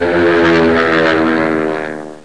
06_JEEP.mp3